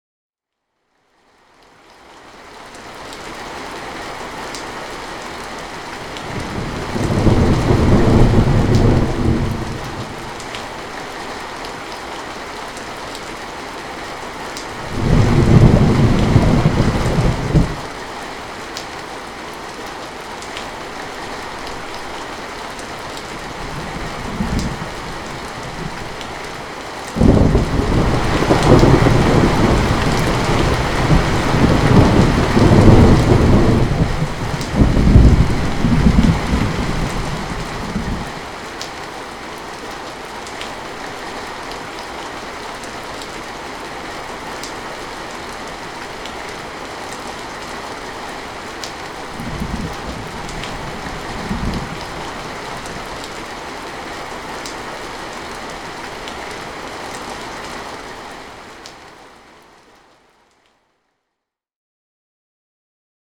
Download Rain and Thunder sound effect for free.
Rain And Thunder